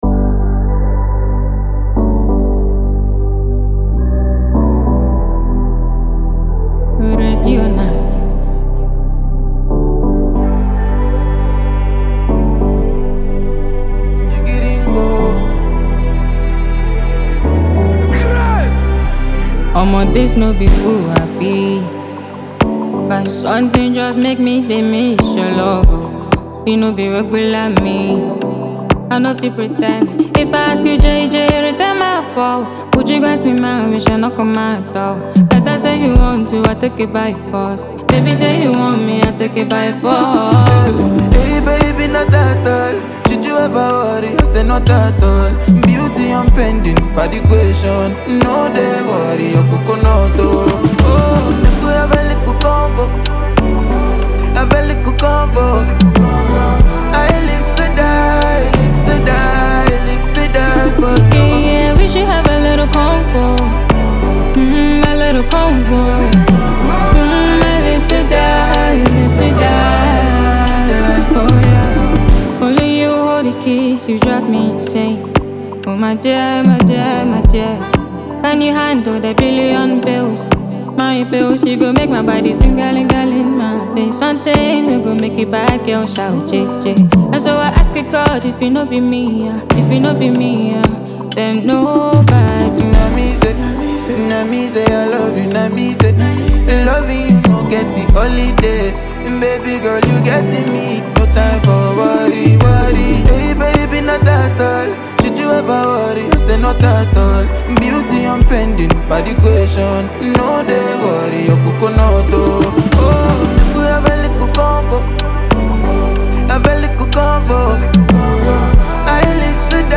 and this track is a smooth Ghana Afrobeat banger.
The track blends soulful vibes with crisp production.
It’s emotional but danceable.